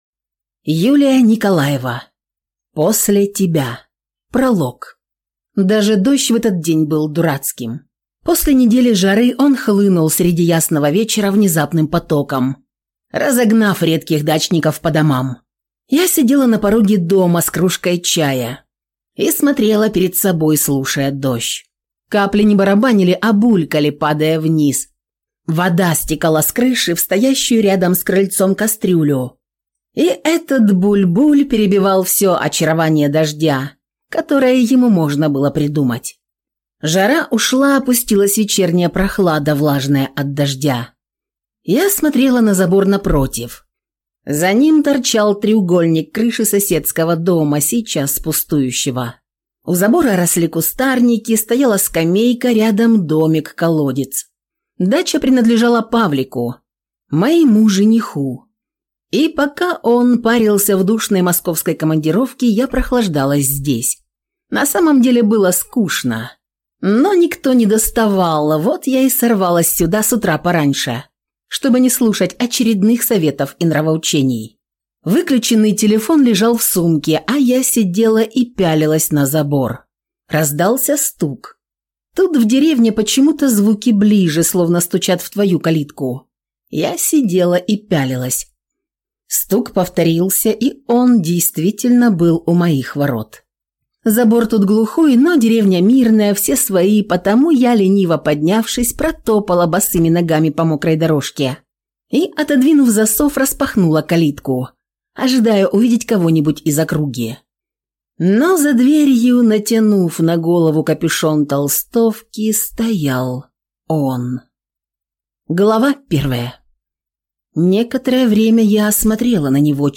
Aудиокнига После тебя